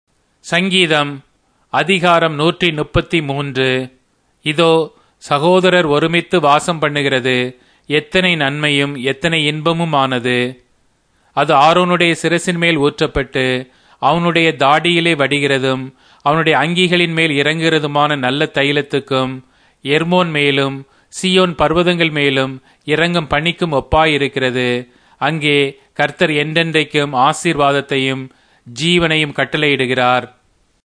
Tamil Audio Bible - Psalms 105 in Irvgu bible version